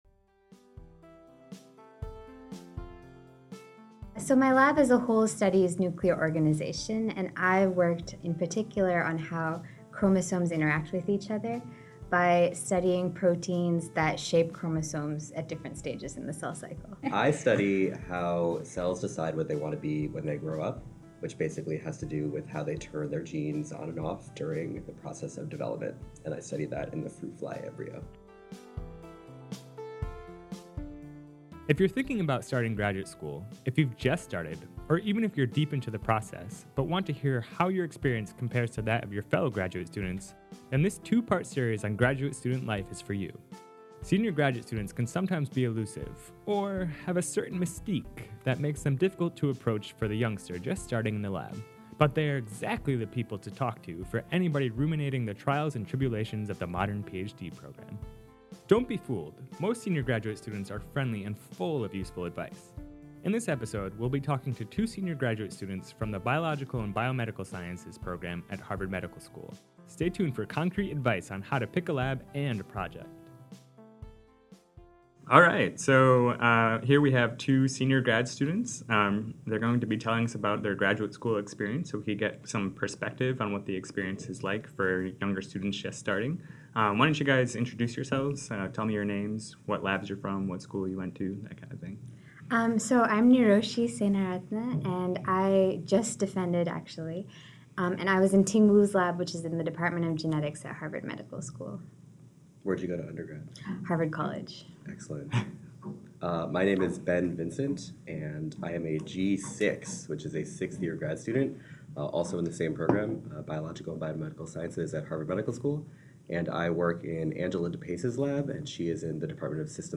Stay tuned for concrete advice on how to pick a lab and a project . Listen to the interview here!